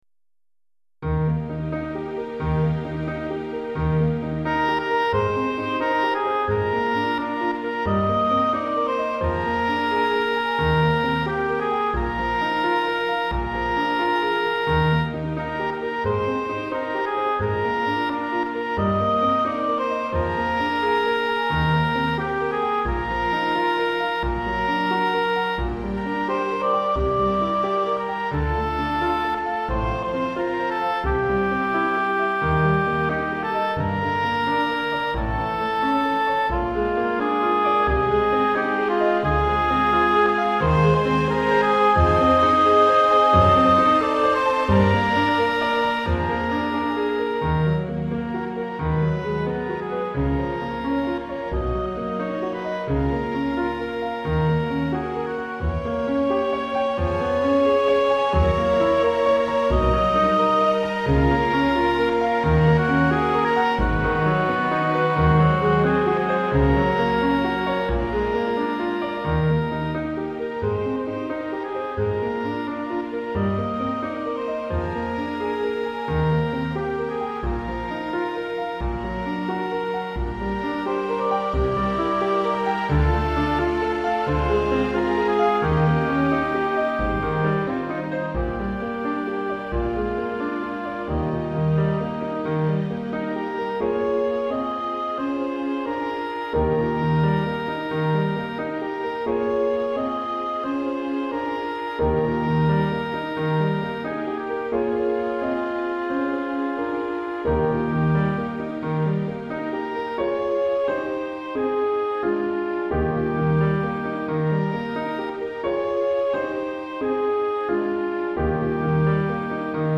Répertoire pour Musique de chambre